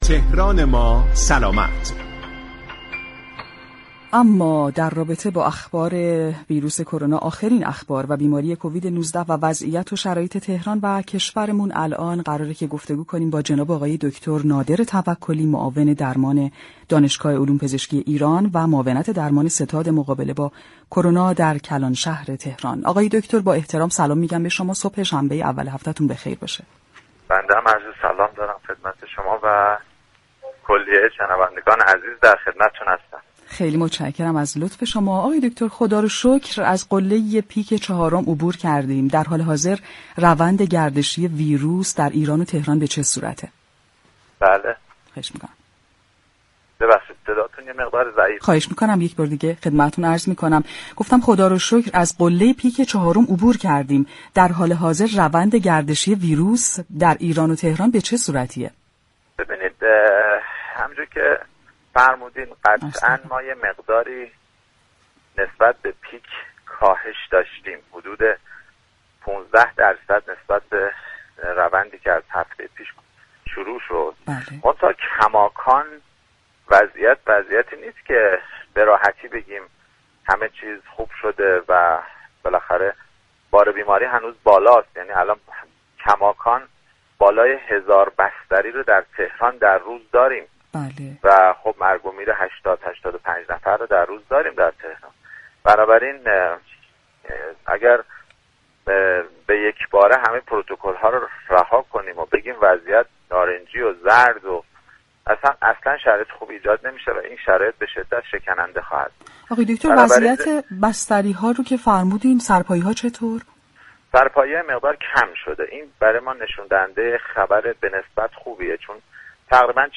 دكتر نادر توكلی معاون درمان ستاد مقابله با كرونای شهر تهران در گفتگو با برنامه «تهران ما سلامت» رادیو تهران با اعلام اینكه طی روزهای اخیر حدود 15 درصد نسبت به قله پیك كاهش داشته ایم گفت: كماكان در شرایطی هستیم كه نمی توانیم بگوییم همه چیز خوب شده است.